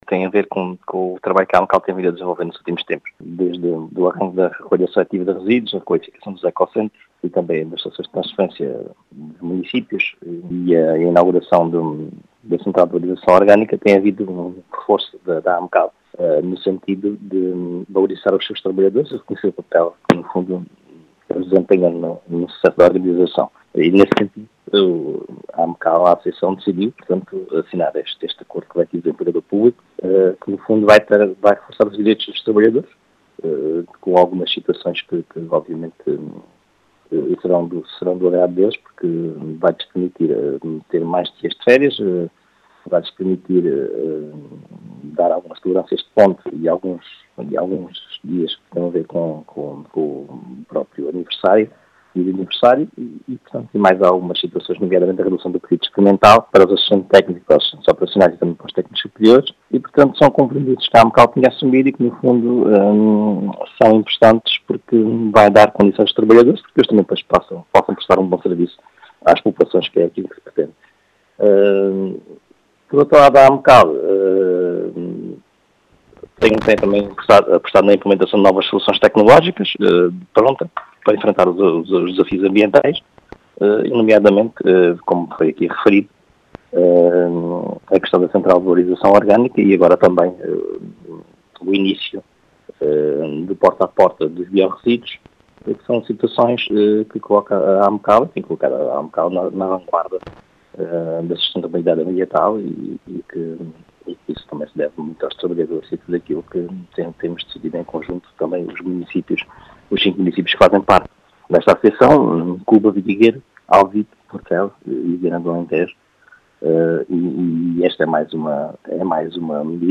As explicações são de João Português, presidente da Associação de Municípios do Alentejo Central.